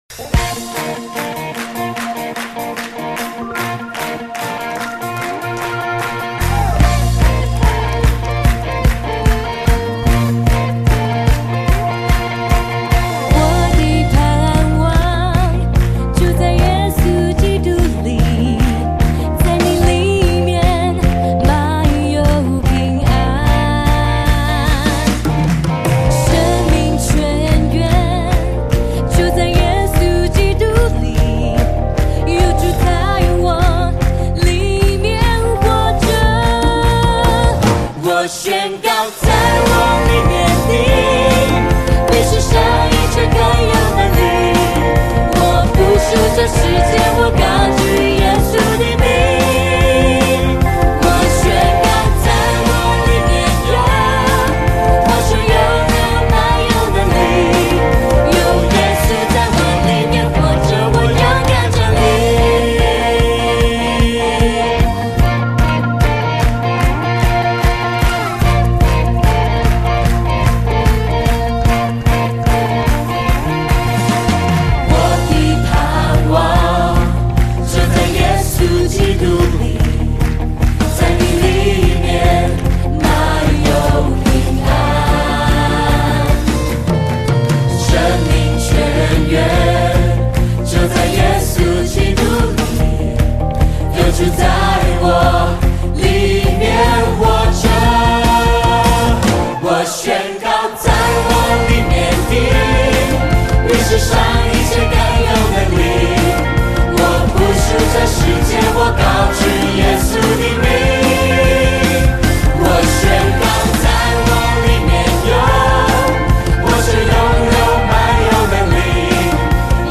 敬拜 100711 上午 10:00